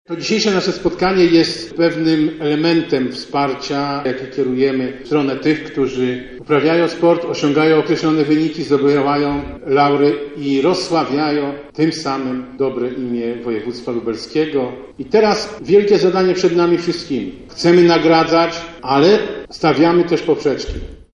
-mówi Sławomir Sosnowski- marszałek województwa lubelskiego.
Dźwięk-Sosnowski.mp3